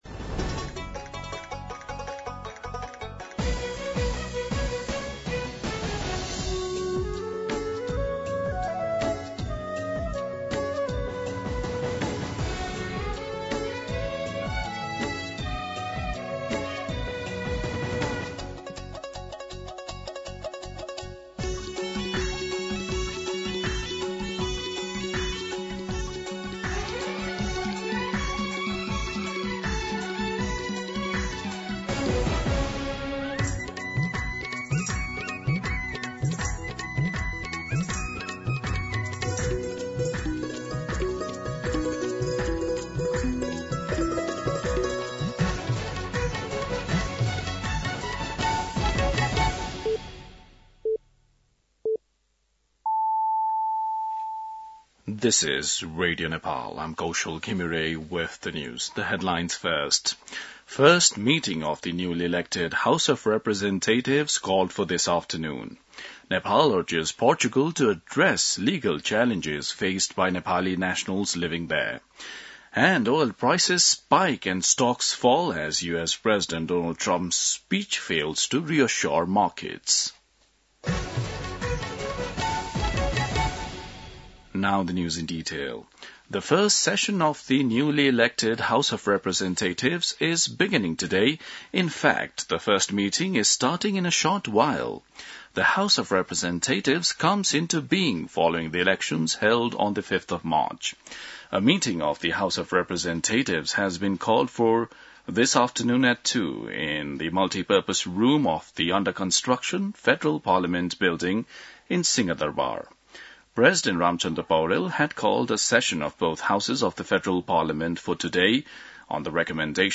दिउँसो २ बजेको अङ्ग्रेजी समाचार : १९ चैत , २०८२